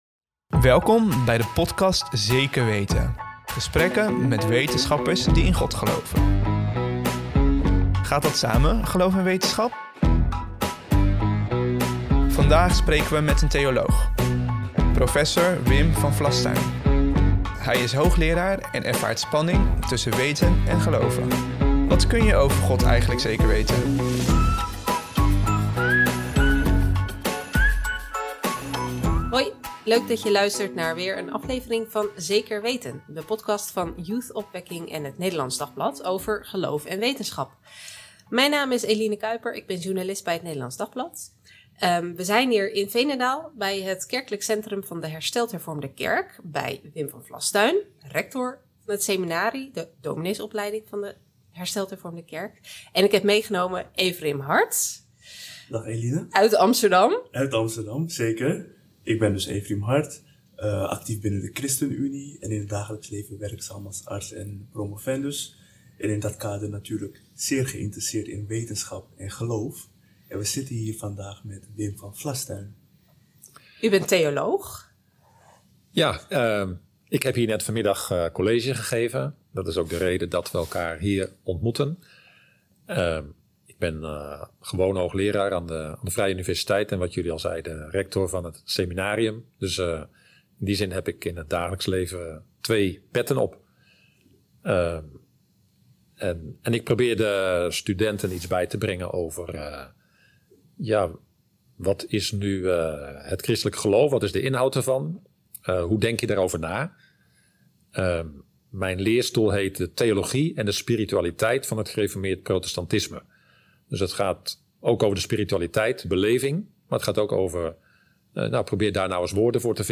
Welkom bij de podcast Zeker Weten, gesprekken met wetenschappers die in God geloven. Gaat dat samen, geloof en wetenschap?